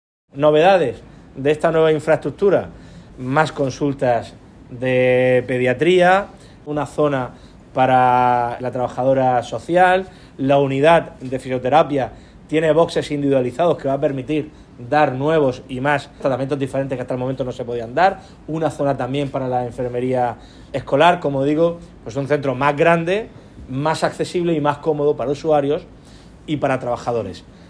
Declaraciones del presidente del Gobierno de la Región de Murcia, Fernando López Miras, sobre el